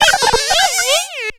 Cri d'Apitrini dans Pokémon X et Y.